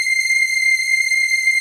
DM PAD3-06.wav